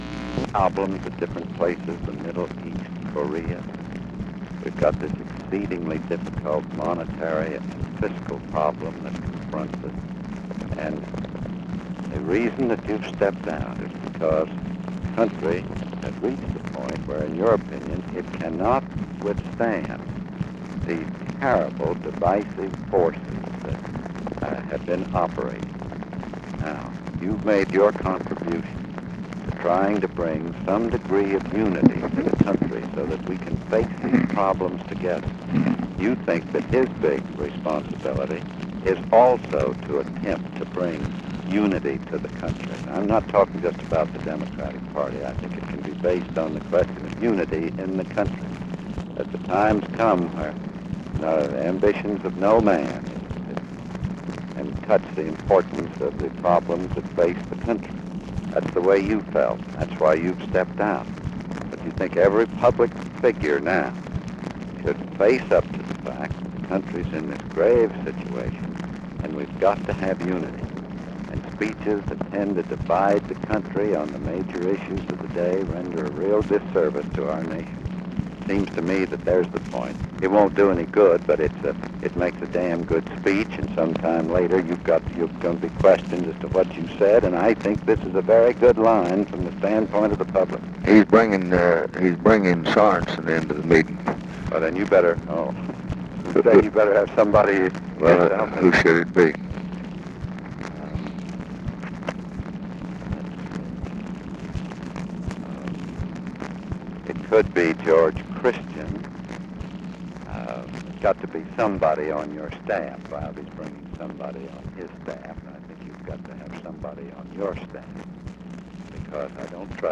Conversation with CLARK CLIFFORD, April 3, 1968
Secret White House Tapes